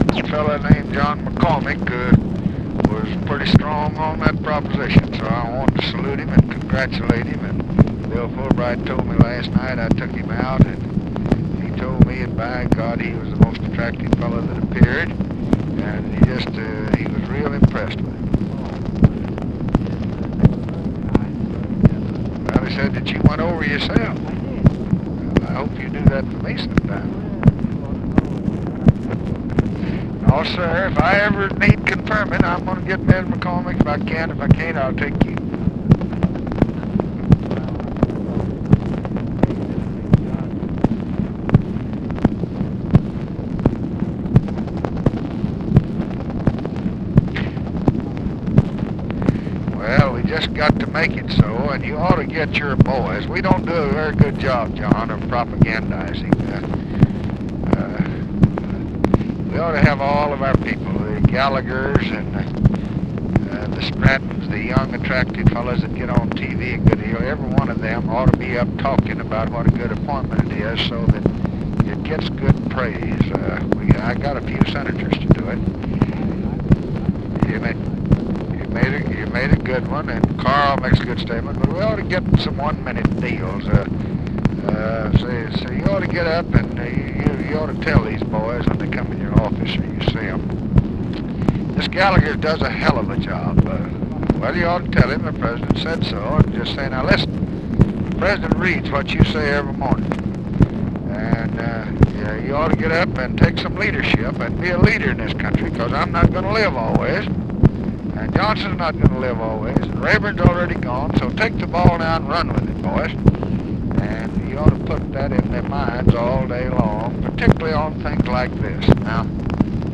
Conversation with JOHN MCCORMACK, July 21, 1965
Secret White House Tapes